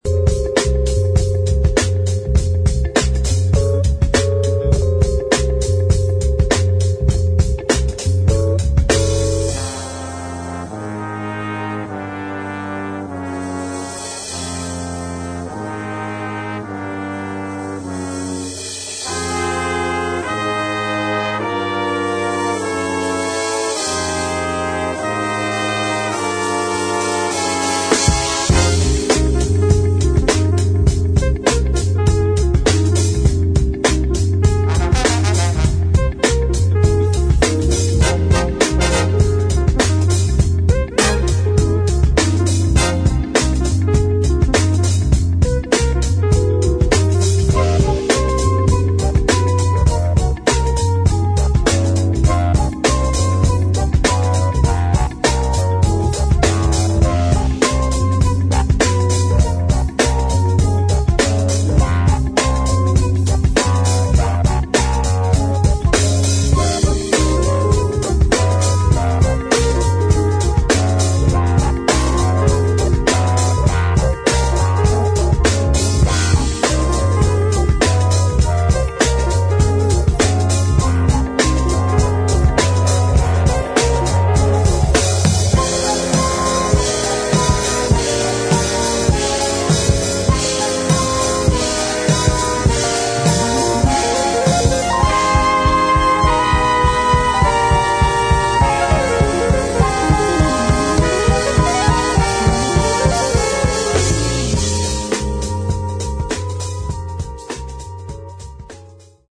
[ HIP HOP / FUNK / SOUL ]